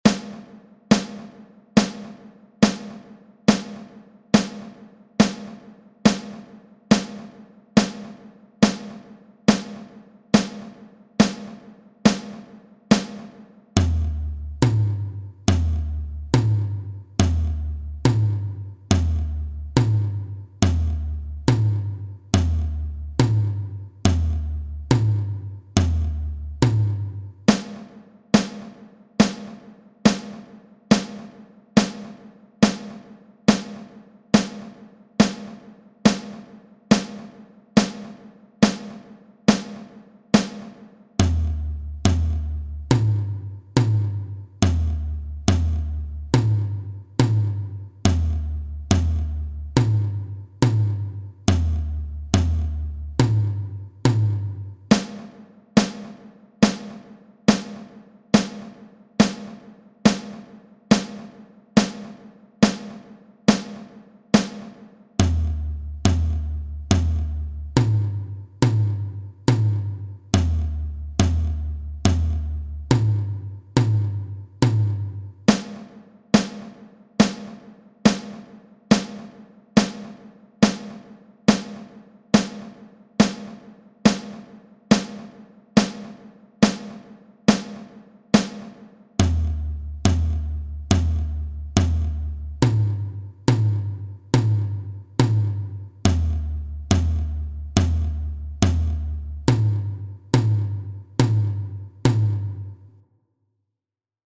Hier gibt es die kompletten Noten als pdf-Datei und ein Hörbeispiel als mp3-Datei:
Übungen für die Hände.mp3